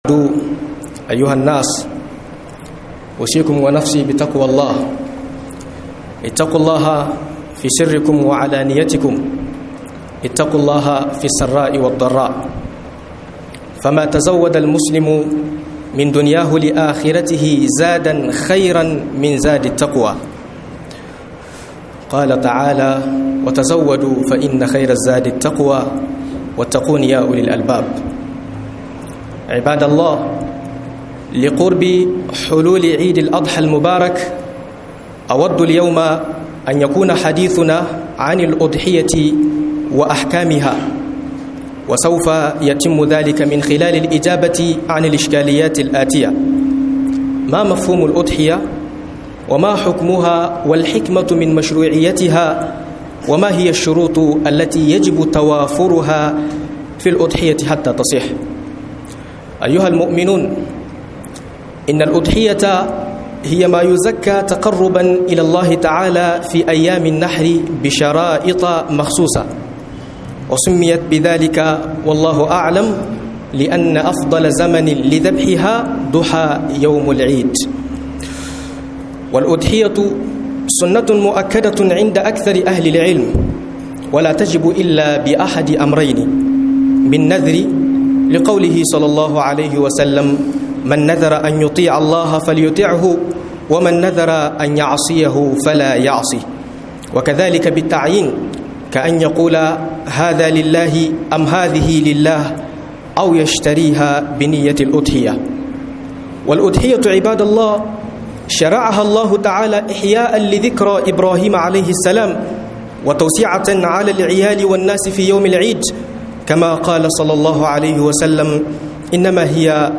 Layya da hukuce_hukuncen ta - MUHADARA